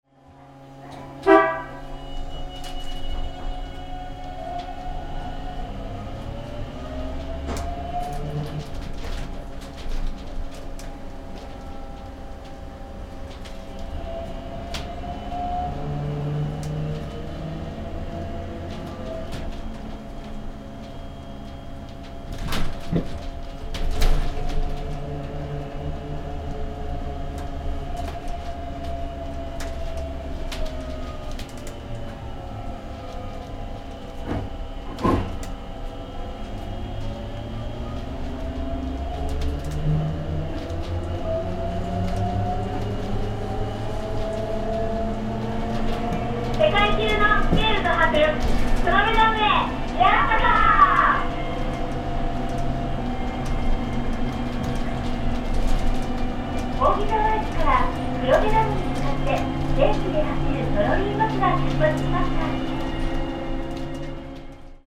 今回のＣＤは、電気バス置き換えを控え最後の活躍をしている３００型を録音しました。ＶＶＶＦインバータもですが、集電装置廻りから発生するトロリーバス特有の走行音をお楽しみください。